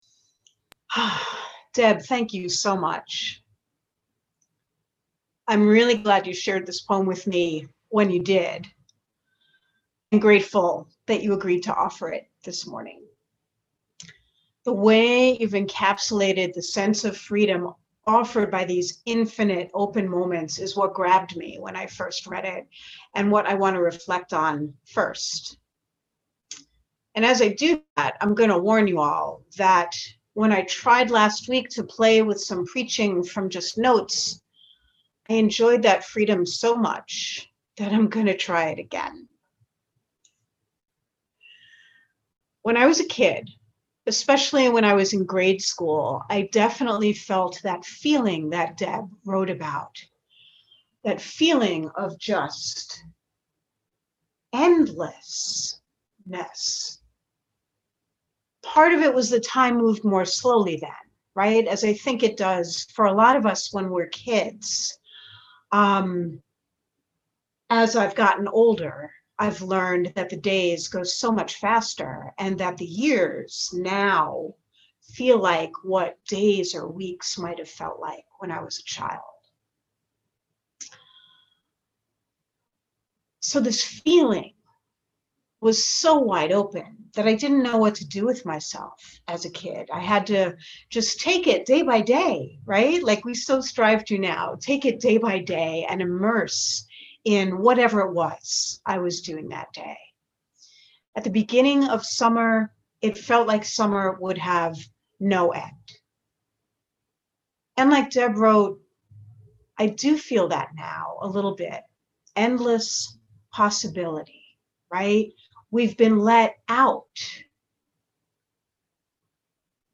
Join us for this service via an on-line Zoom Meeting